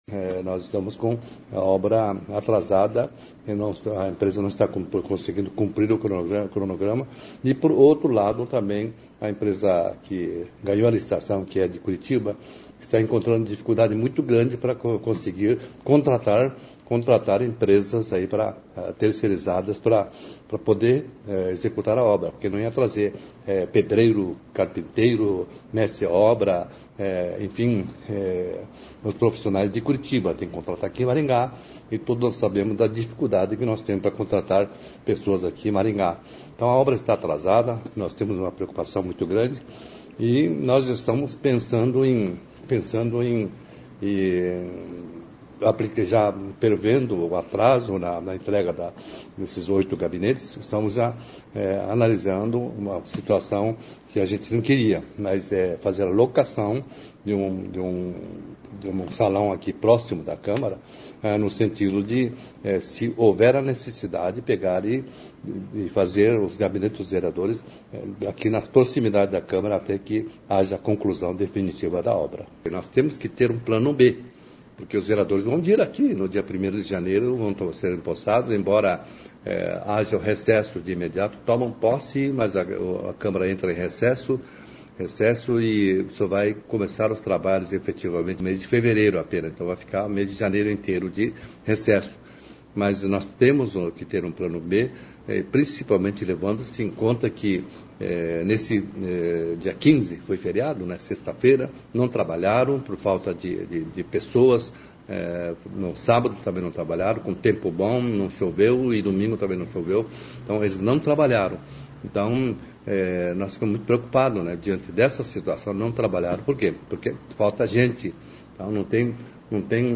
Ouça o que diz o presidente da Câmara, Mário Hossokawa: